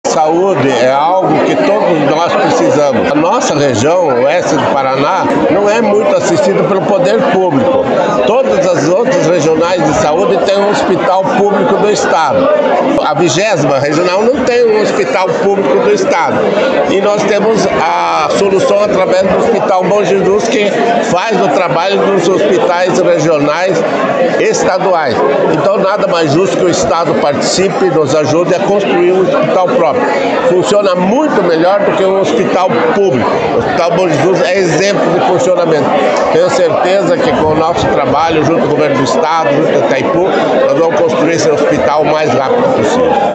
Um dos grandes representantes do Hospital Bom Jesus, deputado federal Dilceu Sperafico, comenta…….